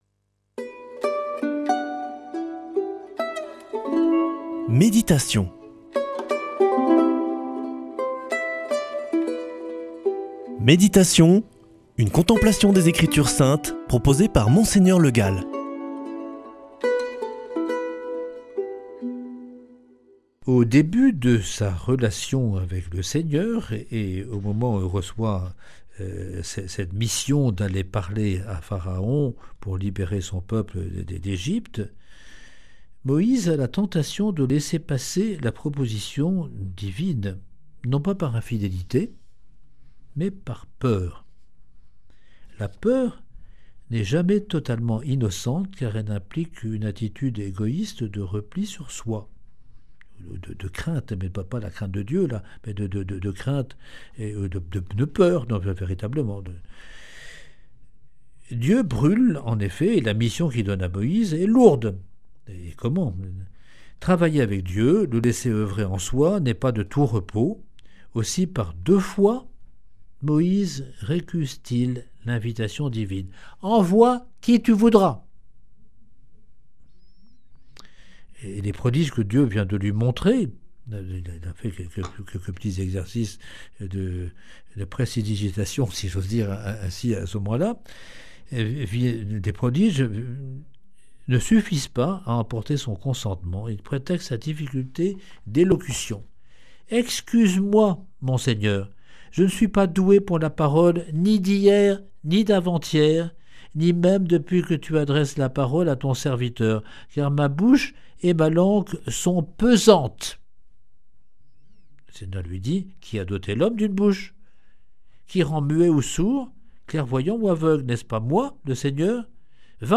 lundi 16 juin 2025 Méditation avec Monseigneur Le Gall Durée 7 min
Une émission présentée par